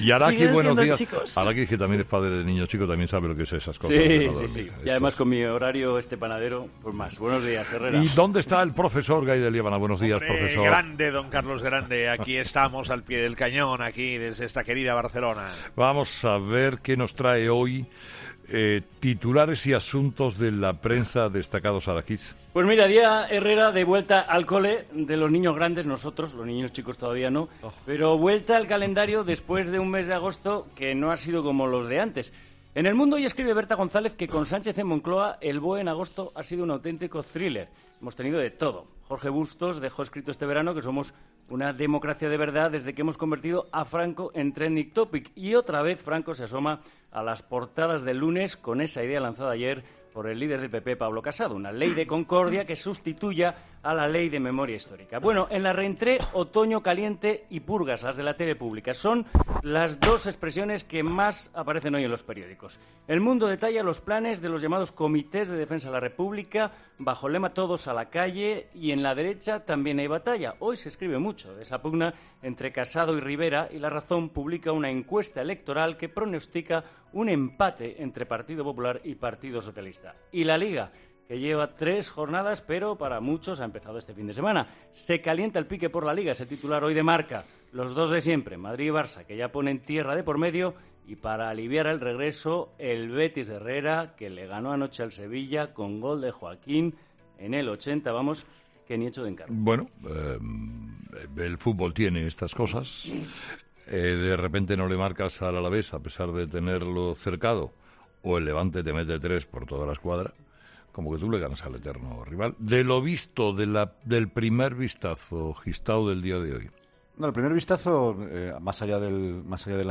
Revista de prensa de este lunes 3 de septiembre